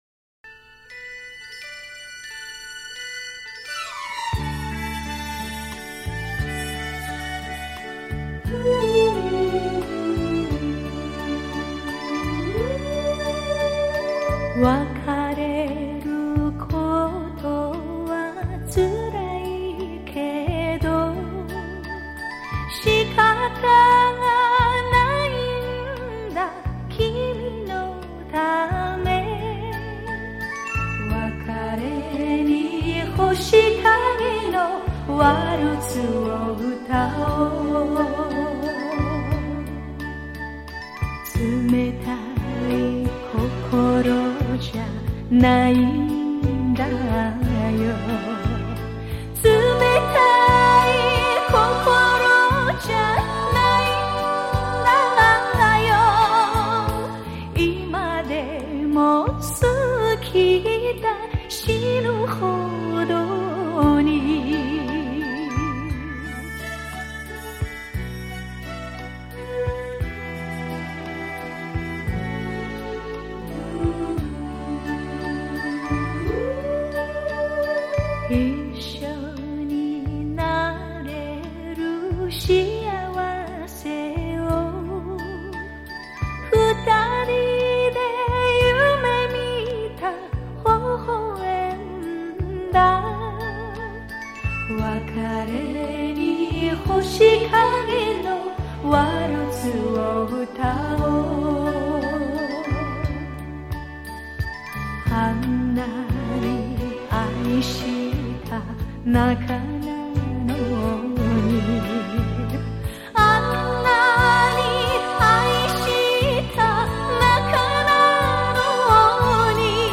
她那甜美的歌声、温柔的情调，溶汇出首首流淌不息的经典情歌。